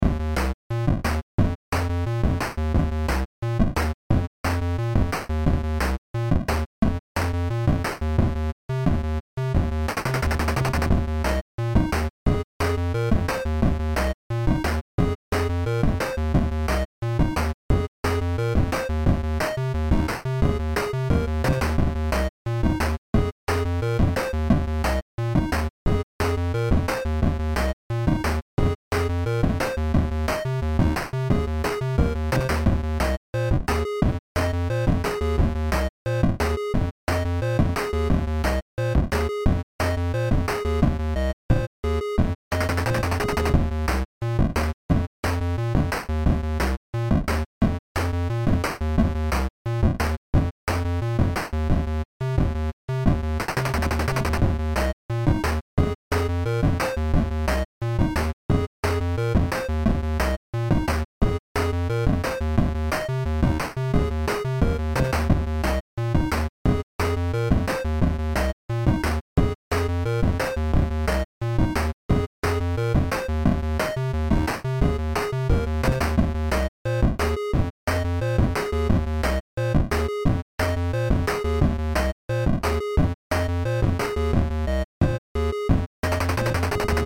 had a little play with some chiptunes today, this is what i came up with. nothing special and short but was a good practice